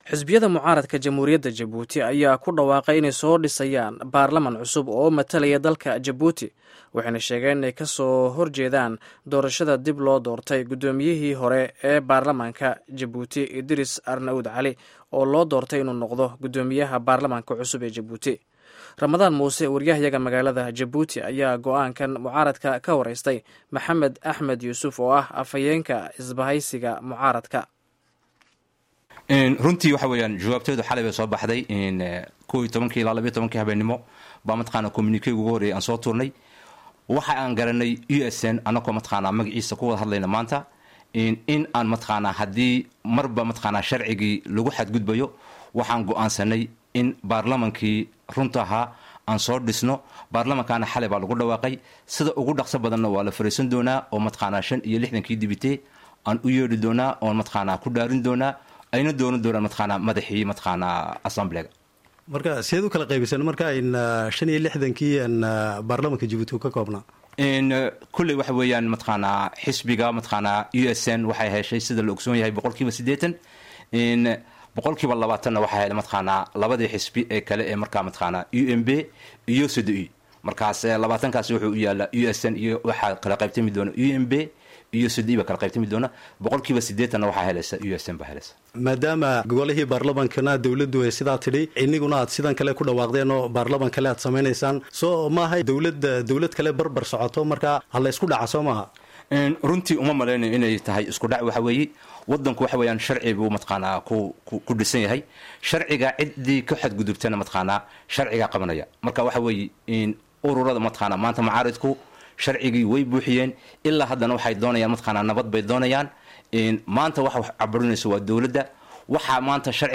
Wareysiga Mucaaradka iyo Gud. Barlamaanka